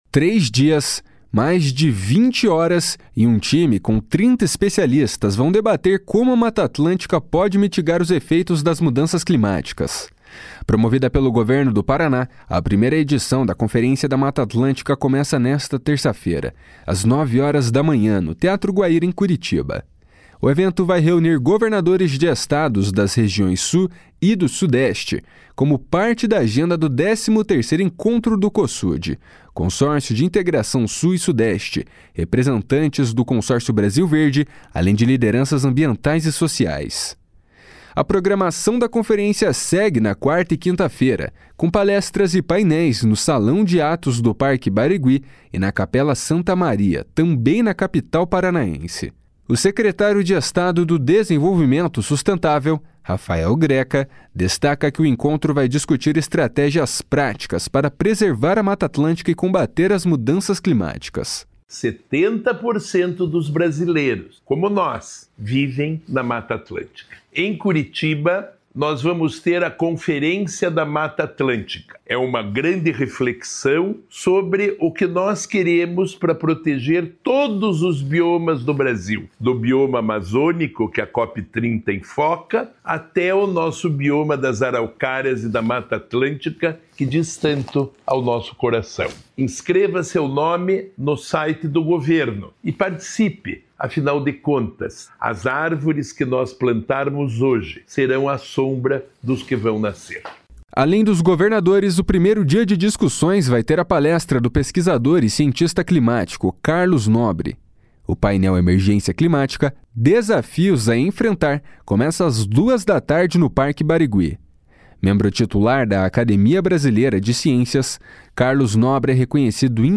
Quem fala sobre o encontro é o secretário estadual de desenvolvimento sustentável, Rafael Greca: